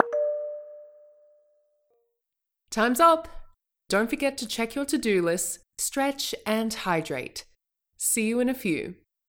break_alert.wav